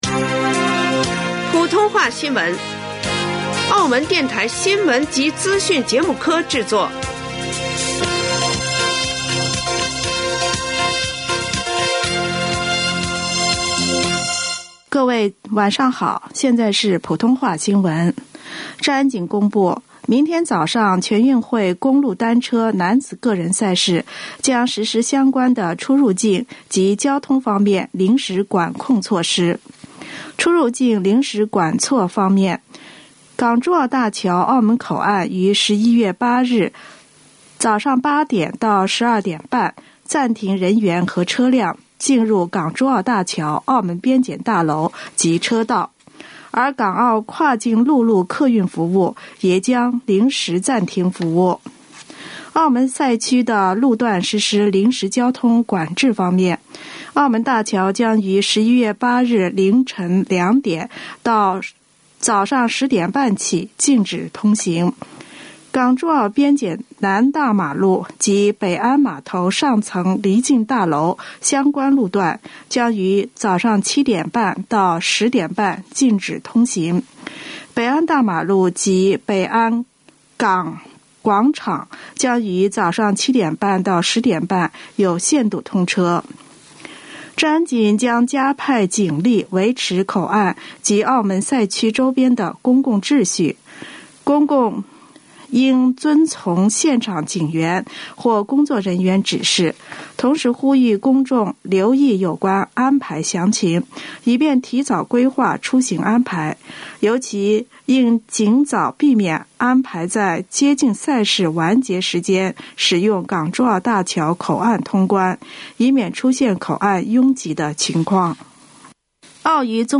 2025年11月07日 19:30 普通話新聞